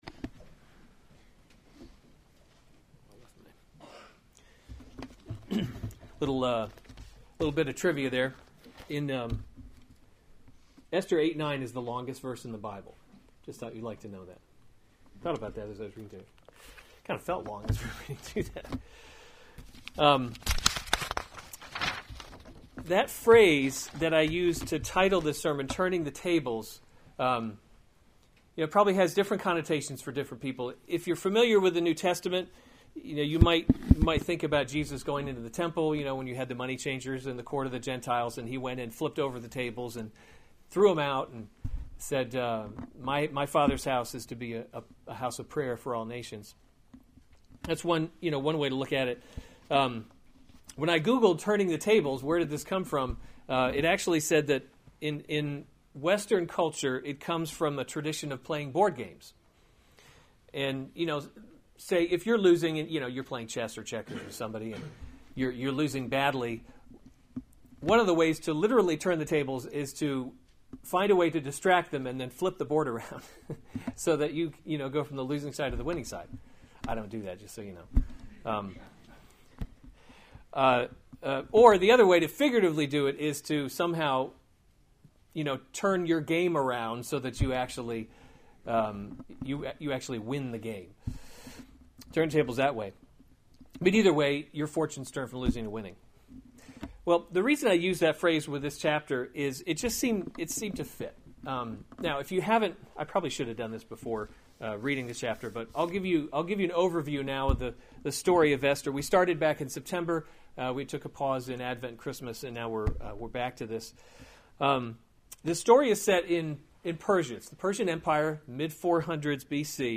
January 7, 2017 Esther: God’s Invisible Hand series Weekly Sunday Service Save/Download this sermon Esther 8:1-17 Other sermons from Esther Esther Saves the Jews 8:1 On that day King Ahasuerus gave […]